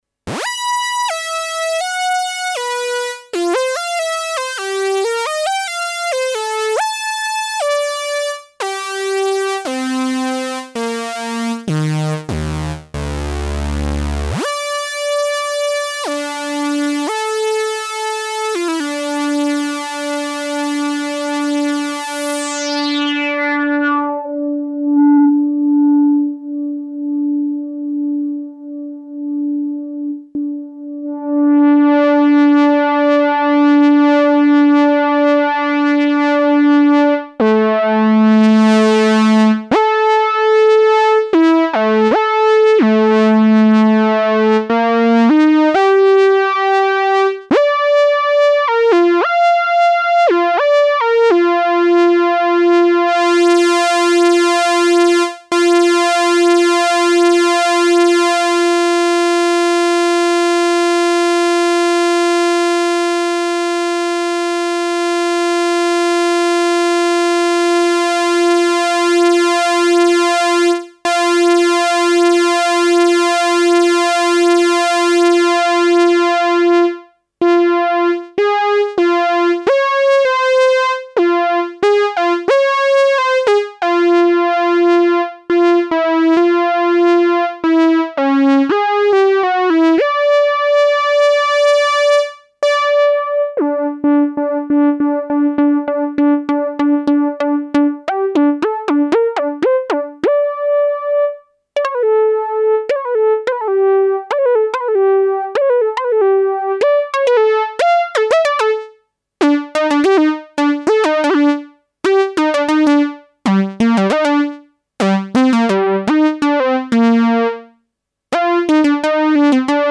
demo of MMG2 with some live tweaking. Since I have a G2 Engine, the tweaking was made via 7 MIDI sliders on my controller keyboard.
PlanlessNoodling.mp3